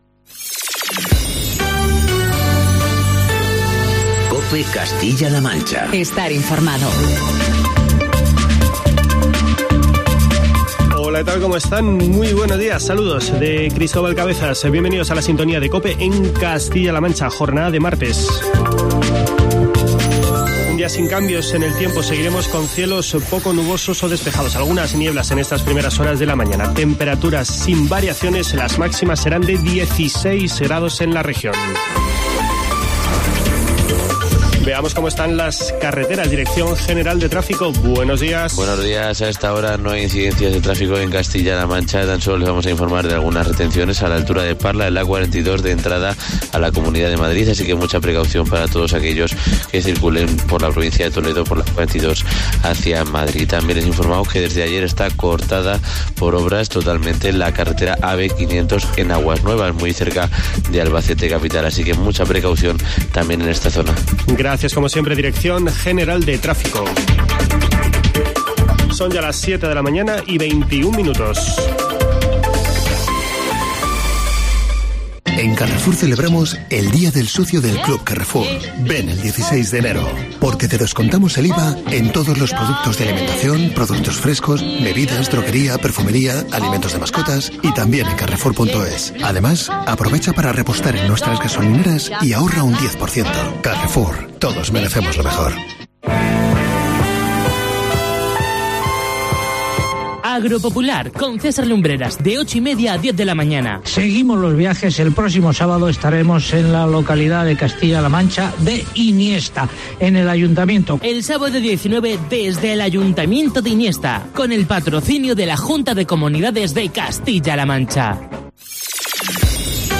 Informativos matinales de COPE Castilla-La Mancha.